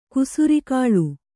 ♪ kurusi kāḷu